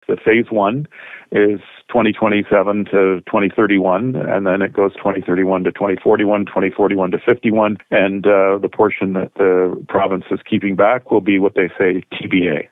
Because of the massive scale of developing what, in essence, will be a community the size of a small town, the undertaking will be phased in over the next couple of decades, explains Sloan.